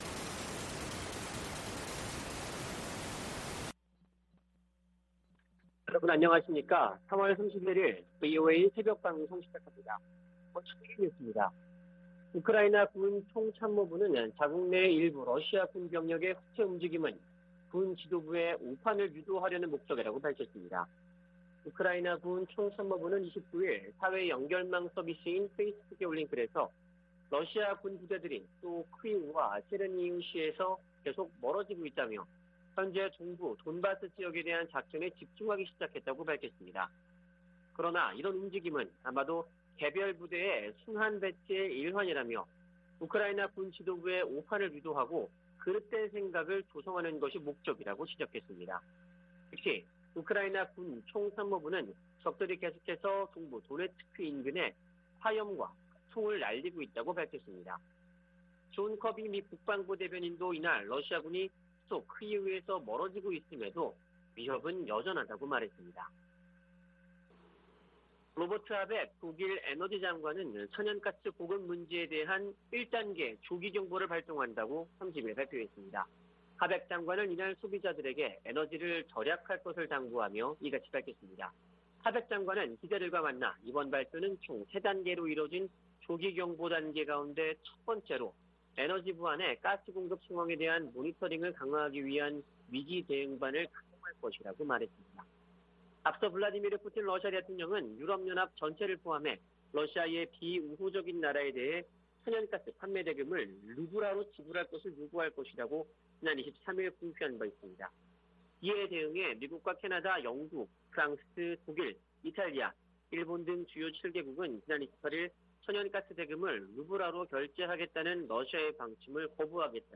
VOA 한국어 '출발 뉴스 쇼', 2022년 3월 31일 방송입니다. 조 바이든 미국 대통령과 리셴룽 싱가포르 총리가 북한의 잇따른 탄도미사일 발사를 규탄하고 대화로 복귀할 것을 촉구했습니다. 북한의 최근 ICBM 발사는 미사일 역량이 증대 됐음을 보여준다고 미 인도태평양사령부 측이 밝혔습니다. 미 국방부가 북한 등의 위협에 대비하는 내용이 담긴 새 회계연도 예산안을 공개했습니다.